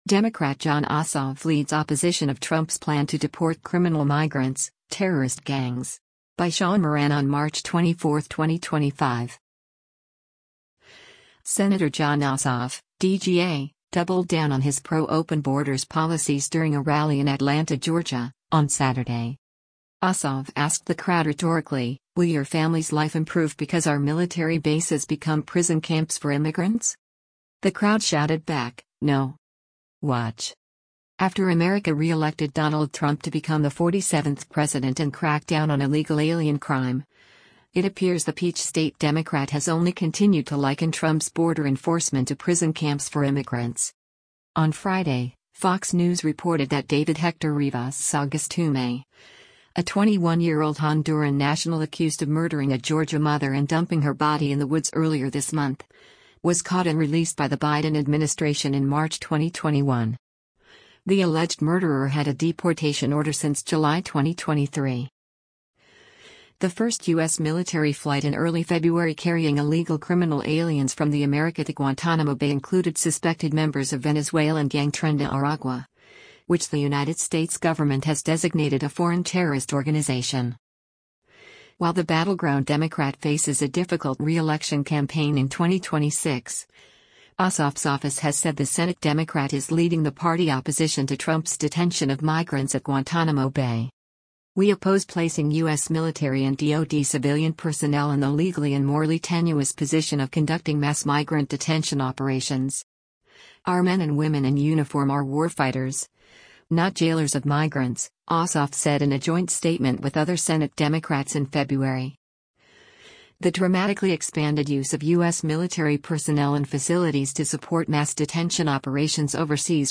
Sen. Jon Ossoff (D-GA) doubled down on his pro-open borders policies during a rally in Atlanta, Georgia, on Saturday.
The crowd shouted back, “No!”